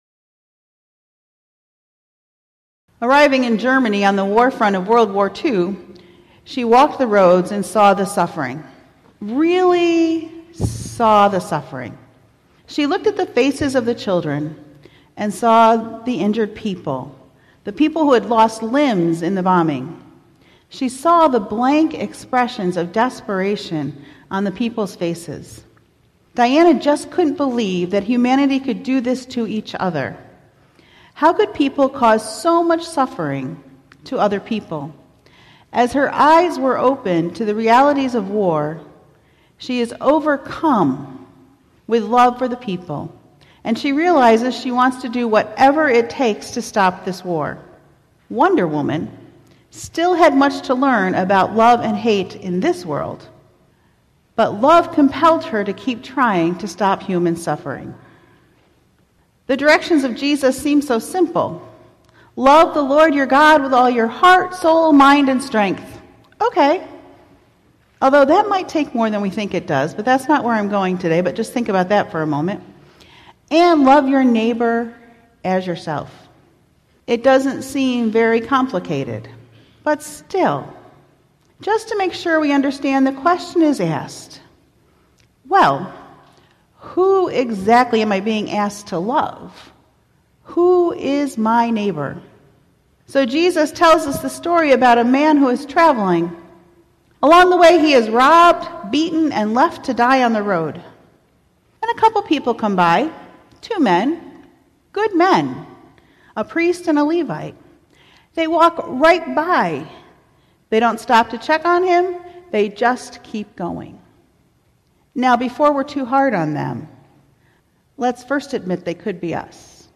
7/23/17 Sermon, “Go and Do Likewise”